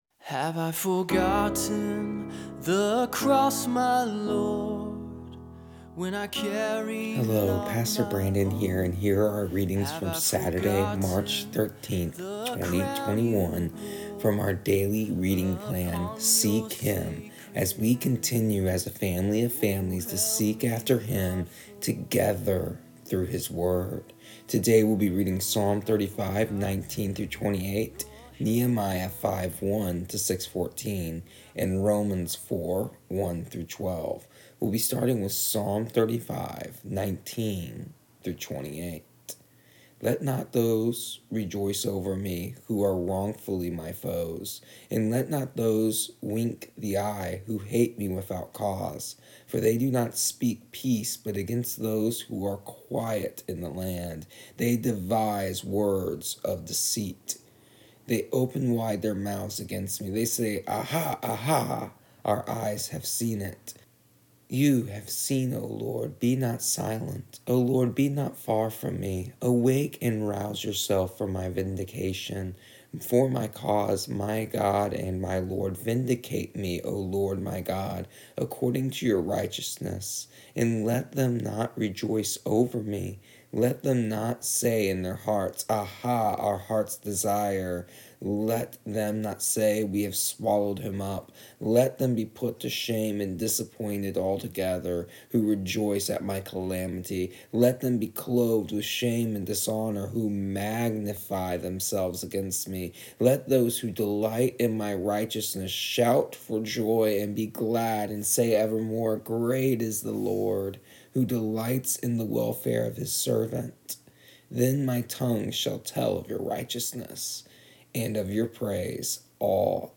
Here is the audio version of our daily readings from our daily reading plan Seek Him for March 13th, 2021.